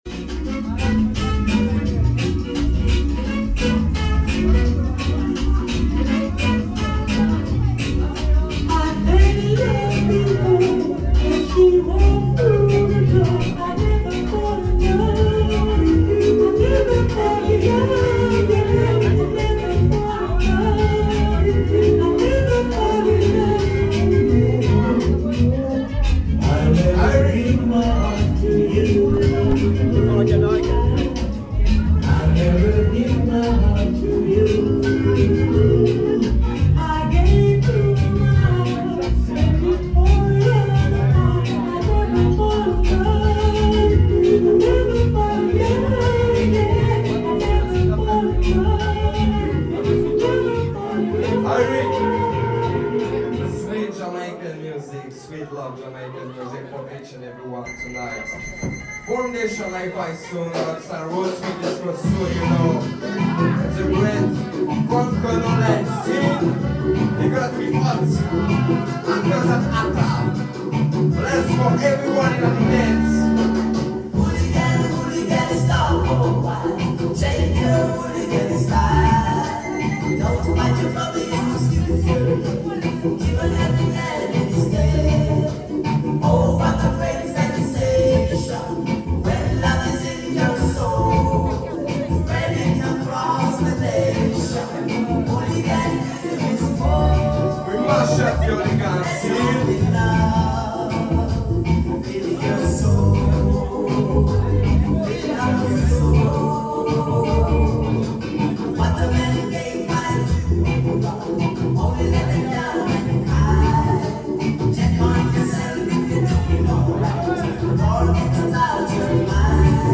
Merci à ceux qui était présent, une grosse ambiance tout au long de la soirée.